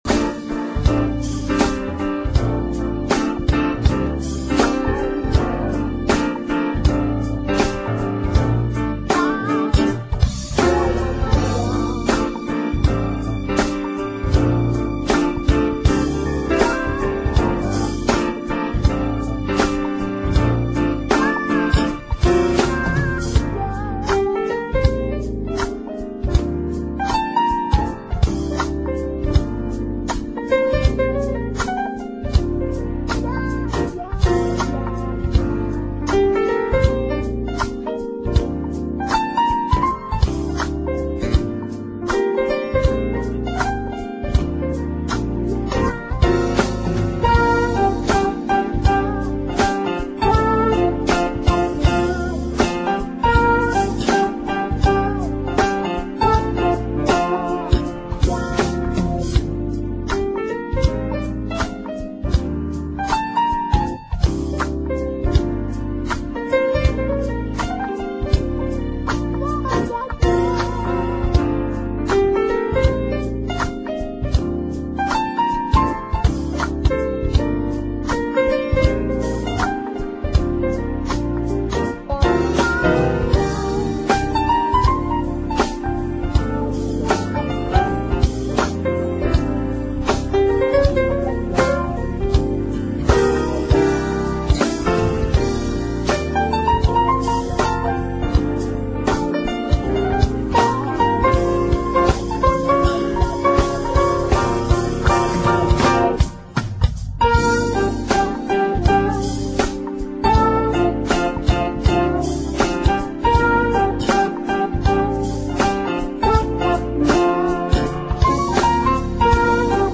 专辑类型：Crossover Jazz Smooth Jazz